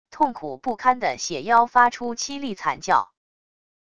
痛苦不堪的血妖发出凄厉惨叫wav音频